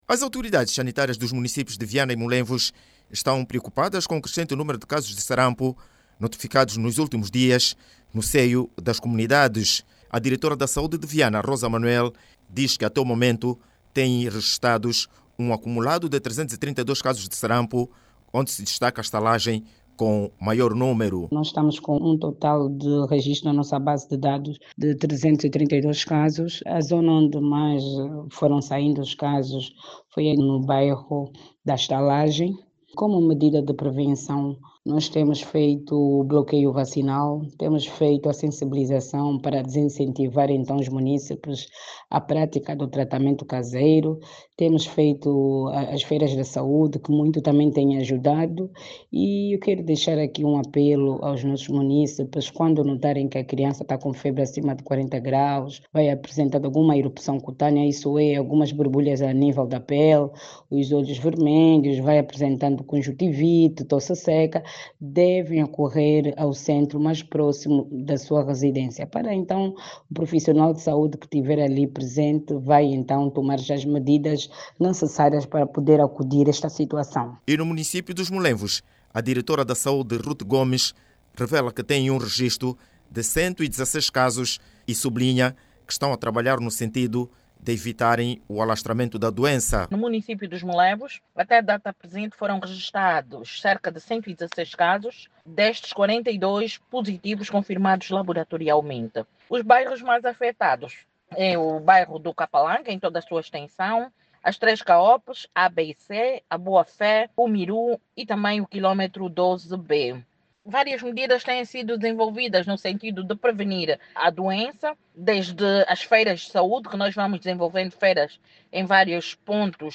Para evitar o surgimento de mais casos as autoridades estão a intensificar a vacinação de bloqueio. Jornalista